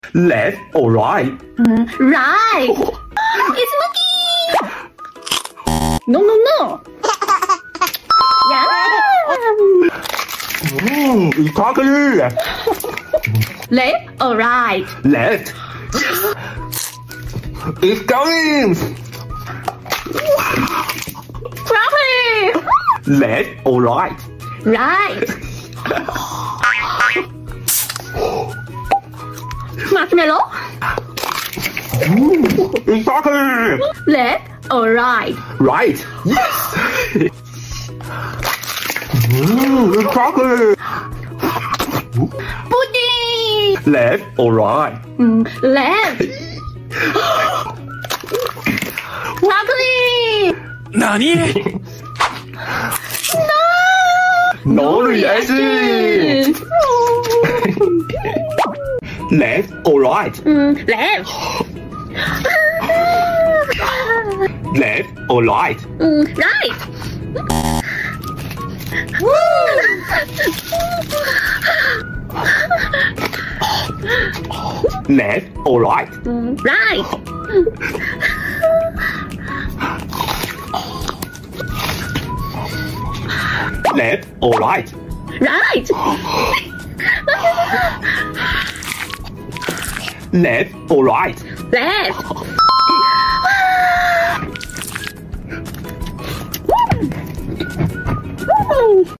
left or right food ASMR sound effects free download
left or right food ASMR challenge